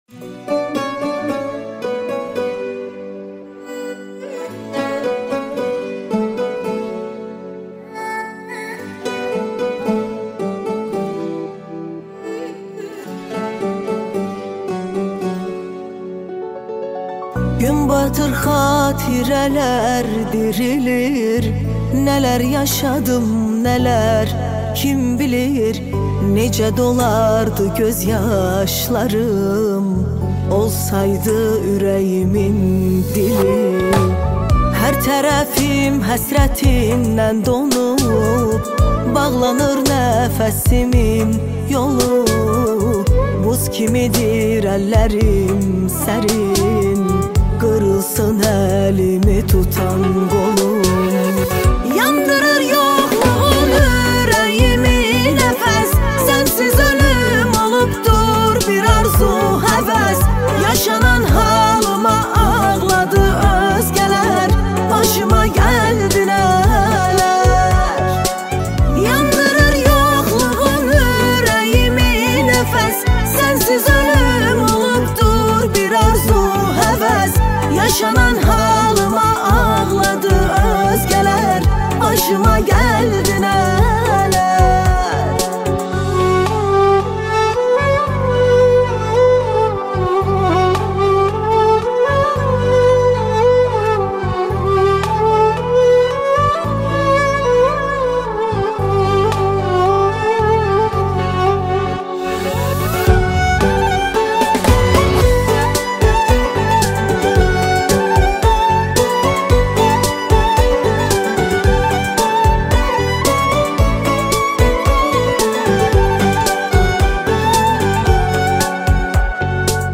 دانلود آهنگ ترکی جدید